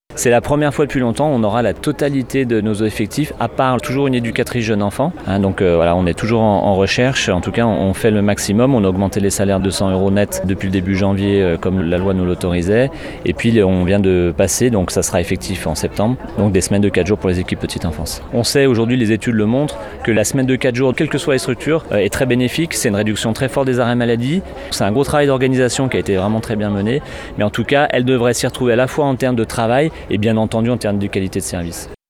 Raphaël Castéra est le maire de Passy.